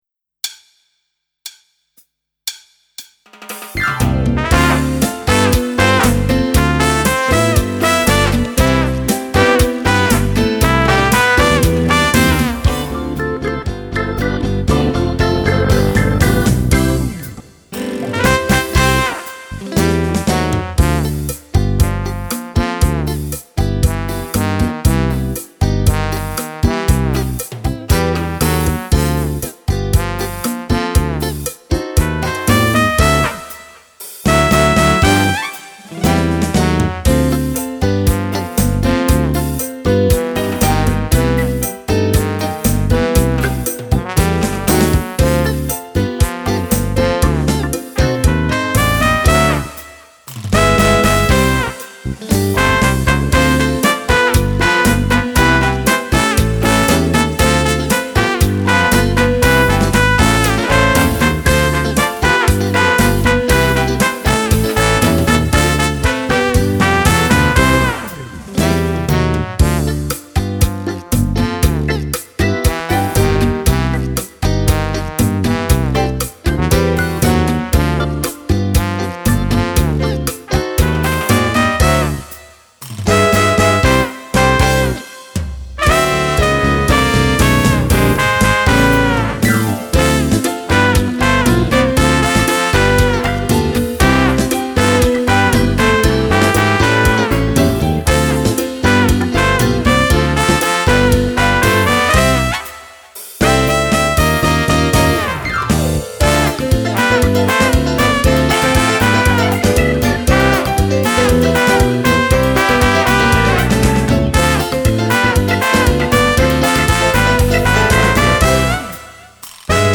cha cha cha
Sax